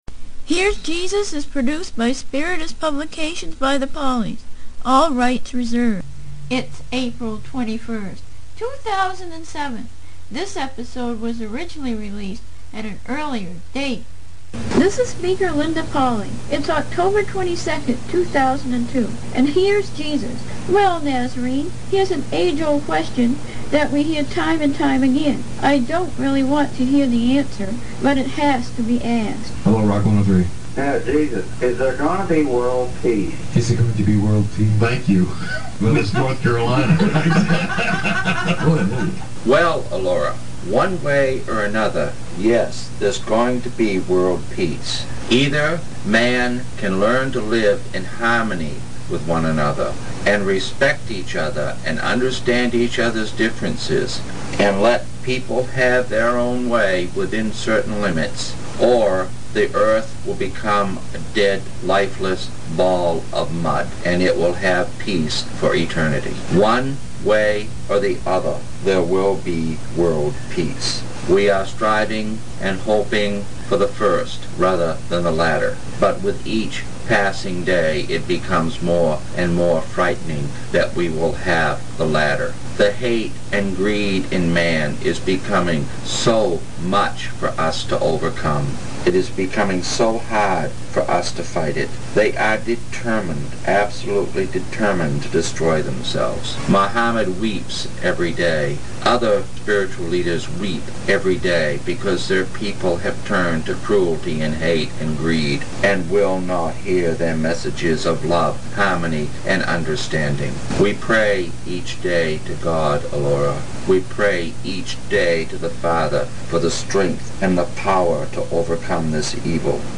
Interviews With